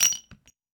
weapon_ammo_drop_04.wav